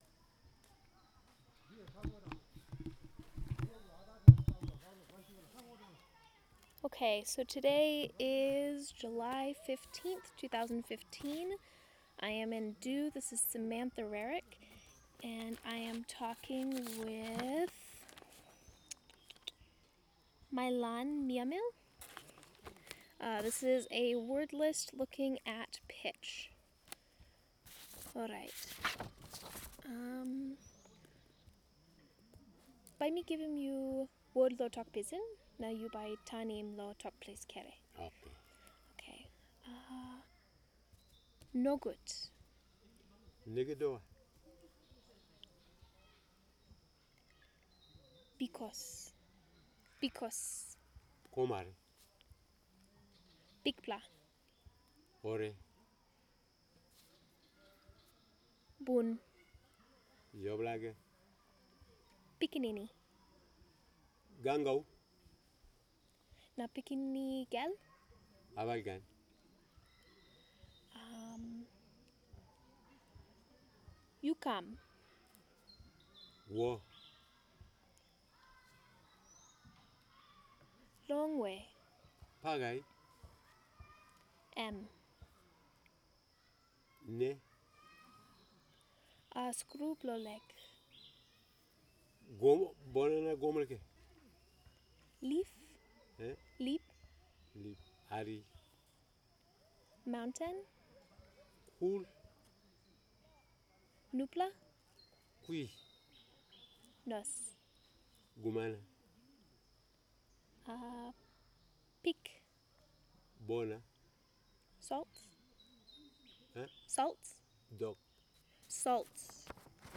Pitch wordlist with and without frames
digital wav file recorded at 44.1 kHz/16 bit on Zoom H2 solid state recorder
Du, Chimbu Province, Papua New Guinea